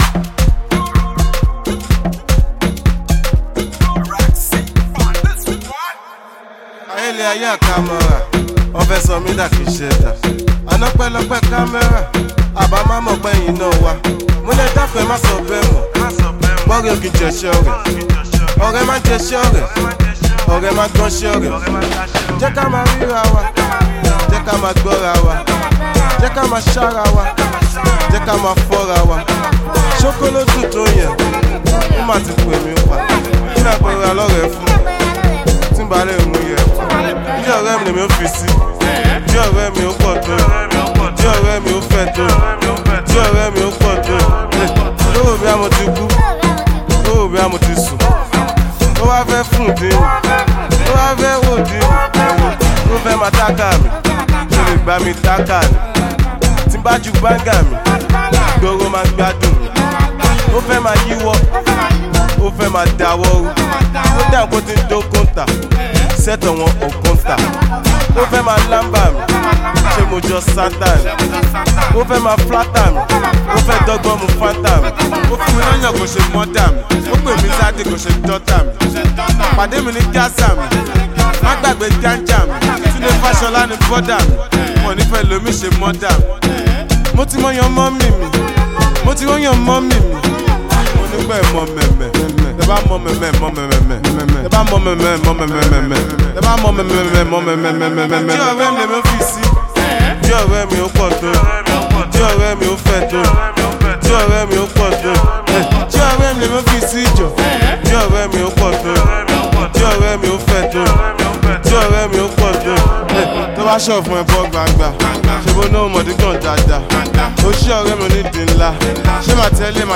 street-hop